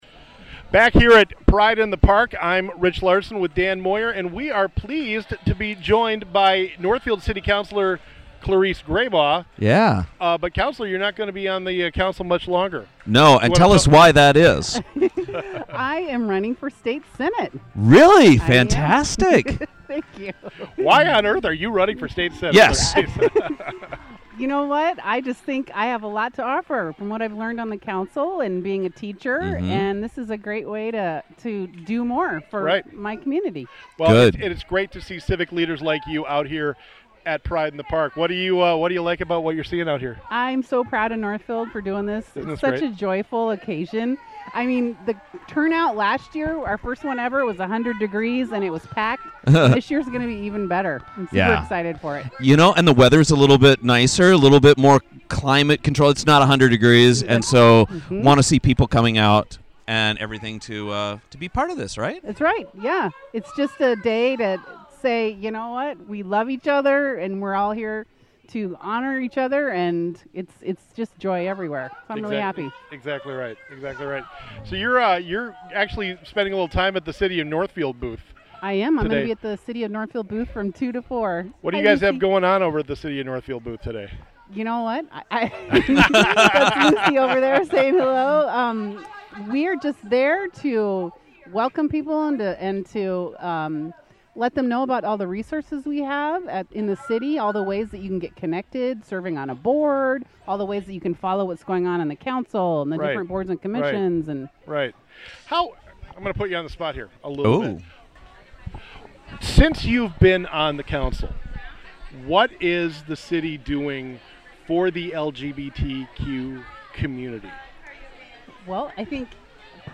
Northfield City Councilor Clarice Grabau from Pride in the Park
Clarice-Grabau-at-Pride-in-the-Park-Final.mp3